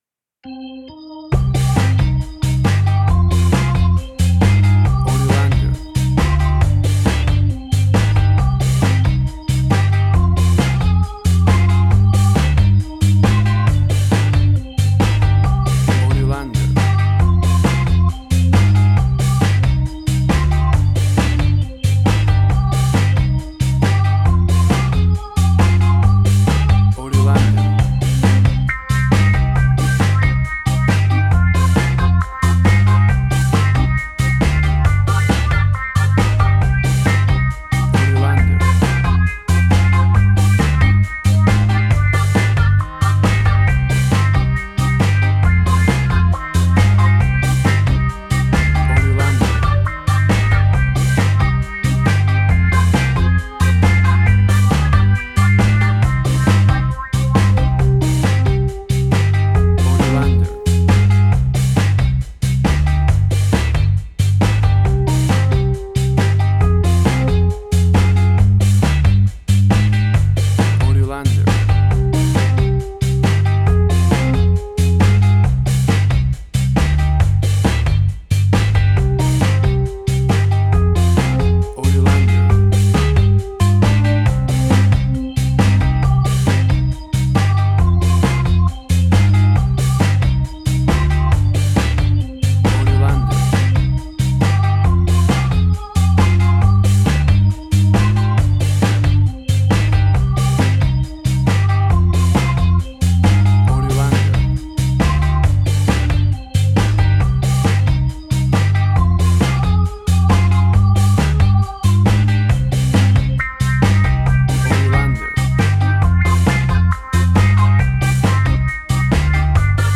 Classic reggae music with that skank bounce reggae feeling.
Tempo (BPM): 136